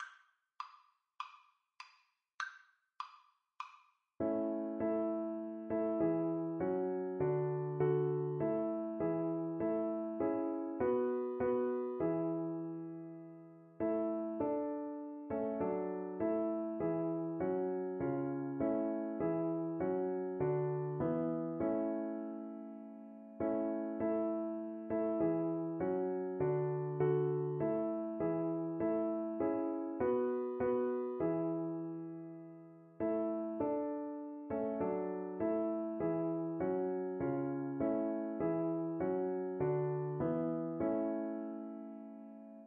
Christmas Christmas Violin Sheet Music While Shepherds Watched Their Flocks
Violin
Traditional Music of unknown author.
4/4 (View more 4/4 Music)
A major (Sounding Pitch) (View more A major Music for Violin )